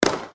shootSnap.mp3